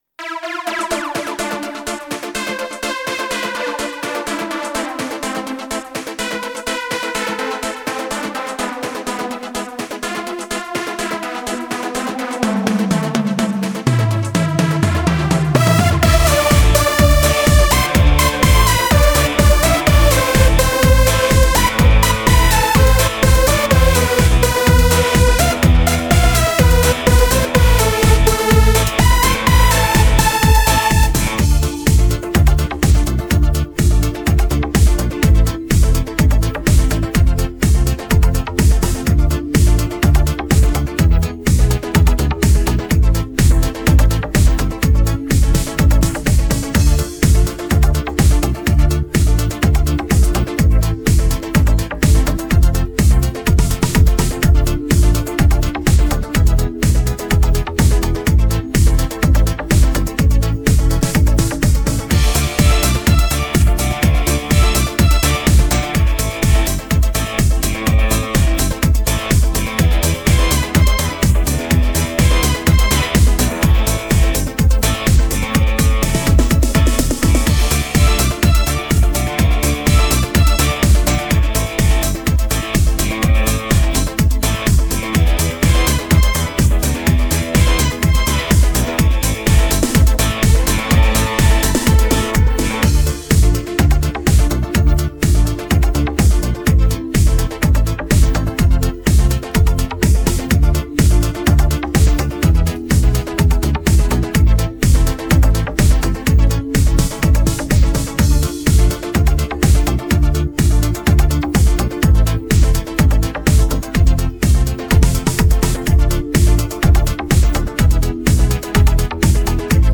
Качественный минус
Шансон